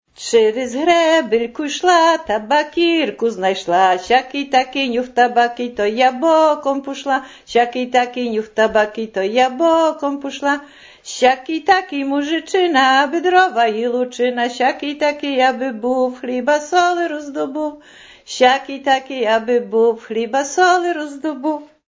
Przyśpiewka weselna
Relacja mówiona zarejestrowana w ramach Programu Historia Mówiona realizowanego w Ośrodku